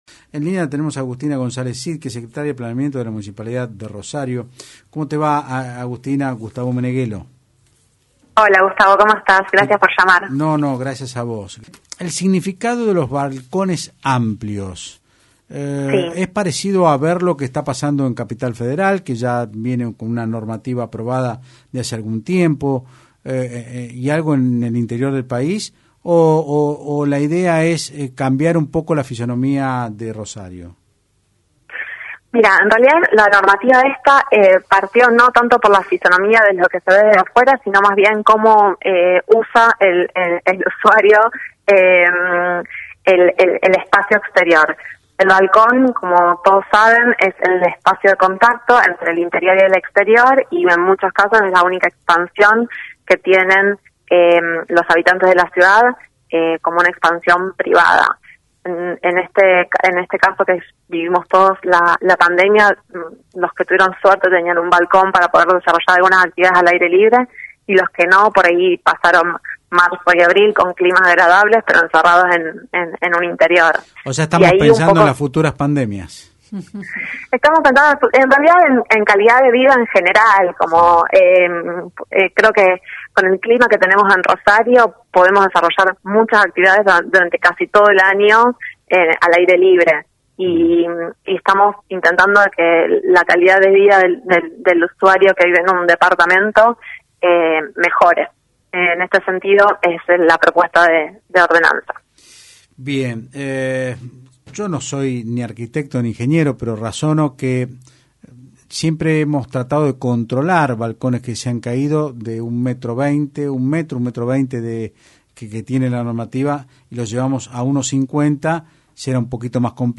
La Secretaria de Planeamiento de Rosario Agustina Gonzalez Cid dijo en Otros Ambitos (Del Plata Rosario 93.5) que se presentó ante la comisión de Planeamiento y explicó la relevancia de estos dos proyectos de ordenanza que buscan generar nuevas condiciones reglamentarias para promover proyectos arquitectónicos que garanticen espacios más generosos, amigables y disfrutables, y relaciones más adecuadas a los usos actuales.